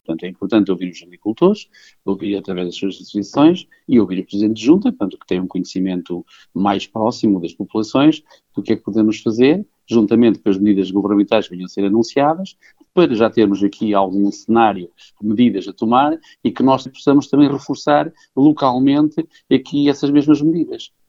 E serão, pelo menos, 2.900 hectares de olival, frutos secos, apicultura, produção pecuária e florestal, entre outras atividades económicas e produtivas, fez saber numa nota à redação da Rádio Terra Quente (CIR), o autarca, que destaca também a importância deste encontro: